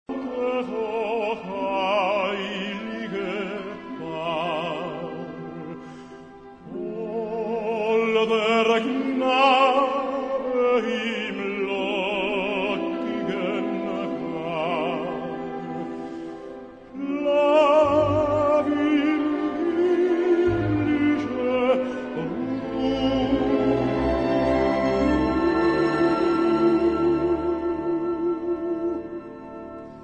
key: C-major